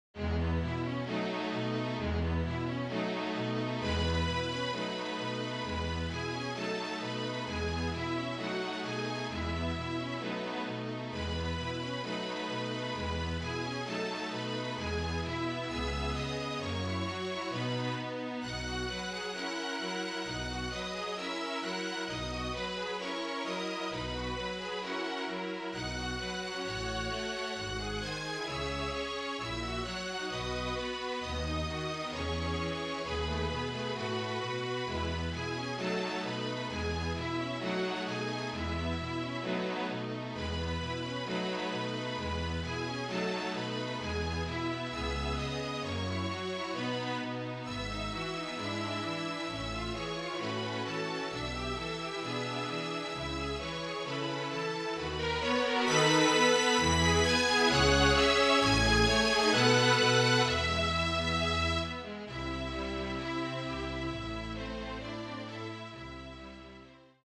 MIDI
for flute, violin, viola, cello and double bass